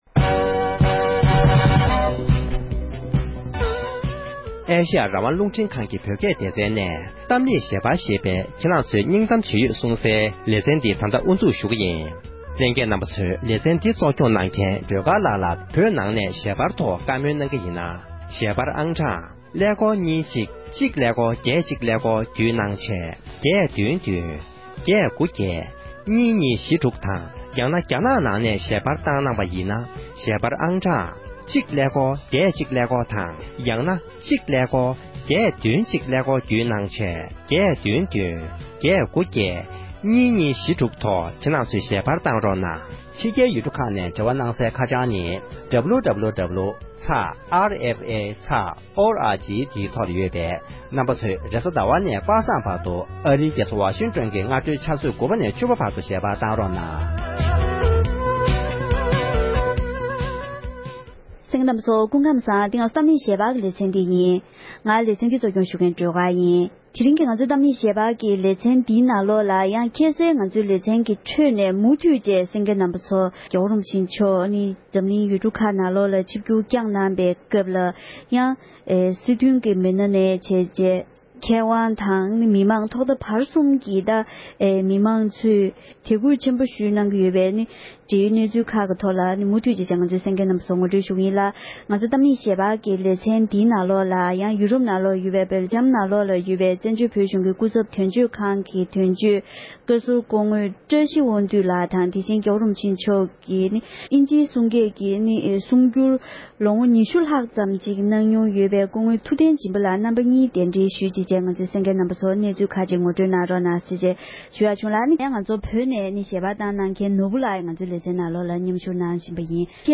འབྲེལ་ཡོད་མི་སྣའི་ལྷན་བཀའ་མོལ་ཞུས་པའི་ལེ་ཚན་གཉིས་པར་གསན་རོགས༎